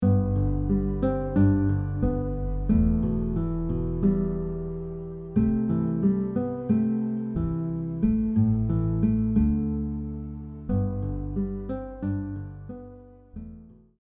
Guitar arrangement